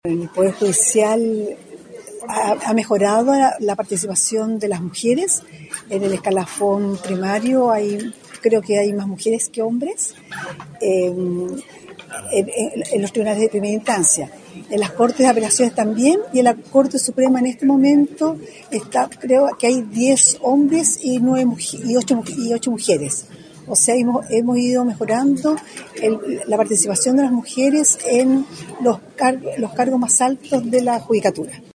Presidenta de la Corte Suprema encabezó acto de conmemoración del 8M en la UdeC - Radio UdeC
La ministra Gloria Ana Chevesich, primera mujer en asumir como presidenta de la Corte Suprema, fue la invitada de honor del acto de conmemoración del Día Internacional de la Mujer, efectuado este viernes 6 de marzo en el auditorio Universidad de Concepción.